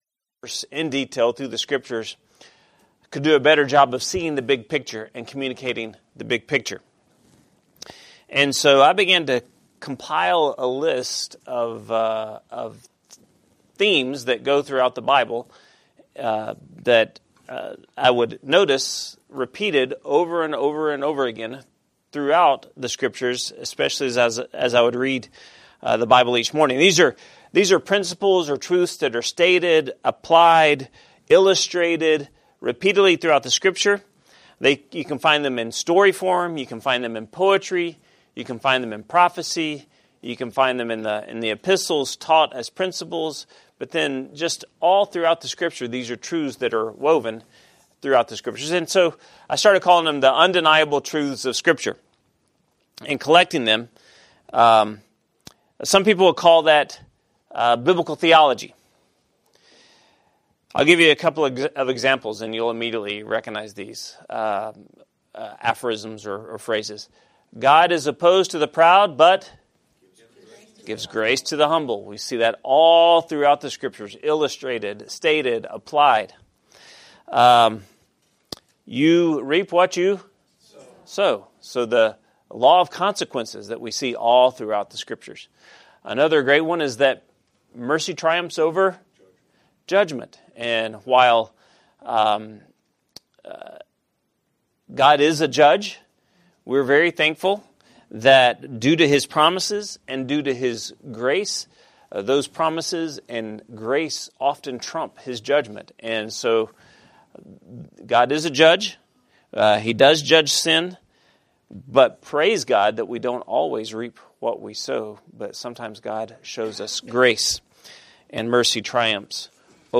Adult Bible Study